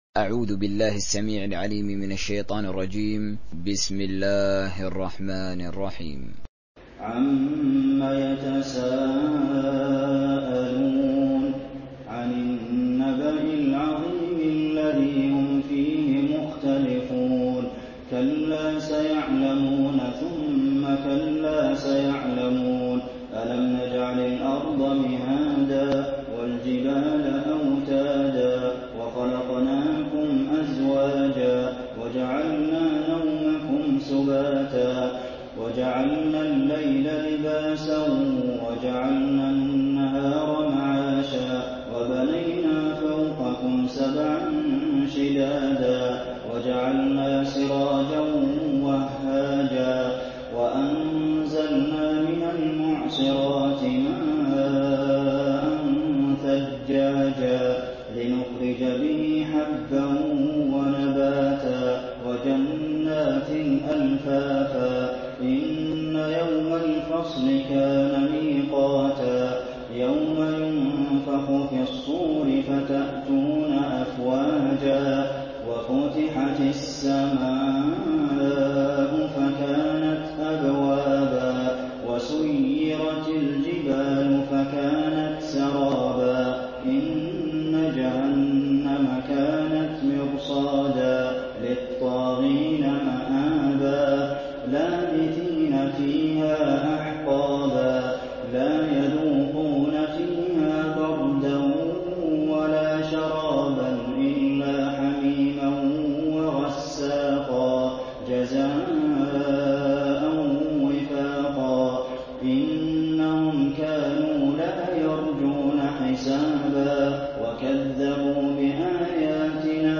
تحميل سورة النبأ mp3 بصوت حسين آل الشيخ تراويح برواية حفص عن عاصم, تحميل استماع القرآن الكريم على الجوال mp3 كاملا بروابط مباشرة وسريعة
تحميل سورة النبأ حسين آل الشيخ تراويح